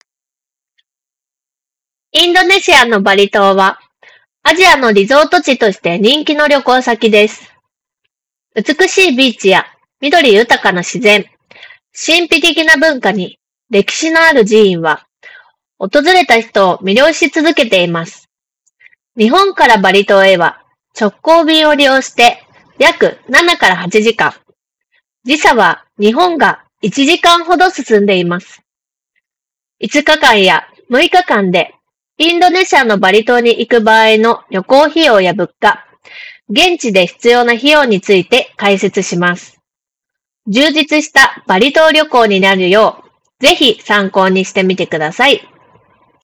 こちらのノイキャンも質が高く、周囲のノイズを取り除き、装着者の声のみをクリアに拾い上げることができていた。
▼OnePlus Buds Ace 2の内蔵マイクで拾った音声単体
録音音声を聴くと、少々割れている感じがするものの、発言内容自体はいたって明瞭に拾い上げることができている。